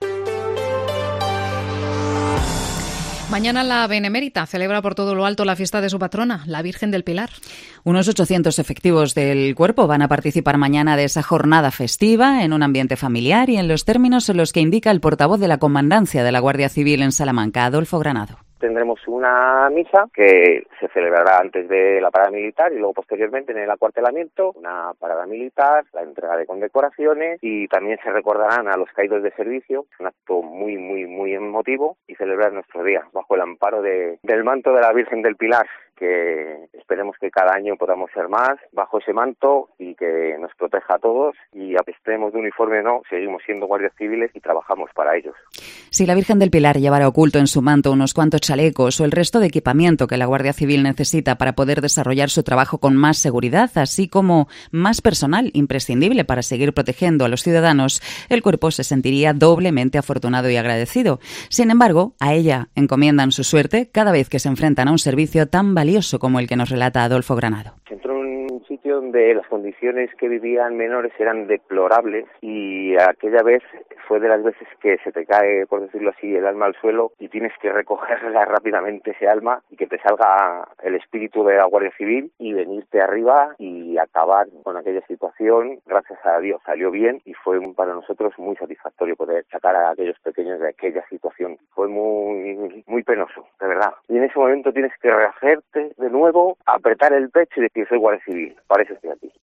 Su voz contagia emoción sincera a través de los micrófonos de COPE Salamanca.